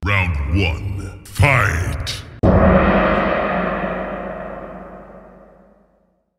MK Round 1 Fight Gong
mk-round-1-fight-gong.mp3